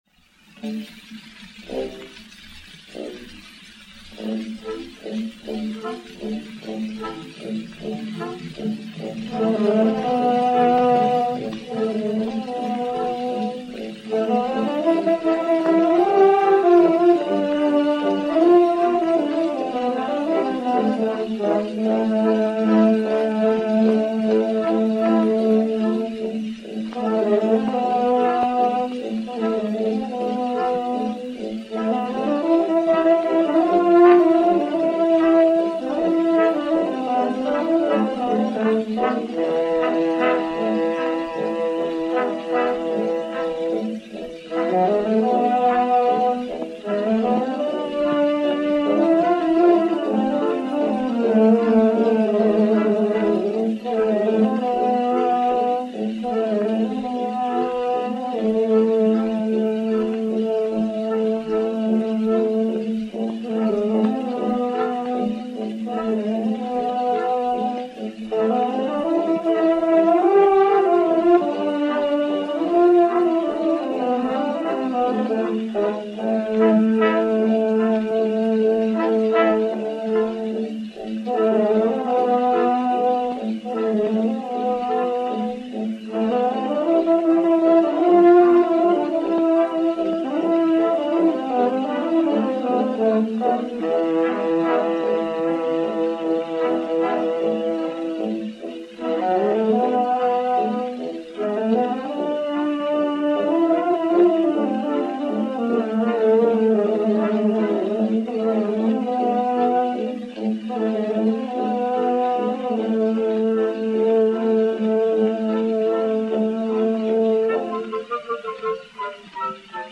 Devant la Madone (souvenir de la campagne de Rome), pièce pour flûte, hautbois, clarinette et violons (nuit de Noël 1864) => partition ; enregistrement ci-dessous
Musique de la Garde Républicaine dir César Bourgeois
Pathé saphir 80 tours n° 6354, mat. 5721, enr. vers 1910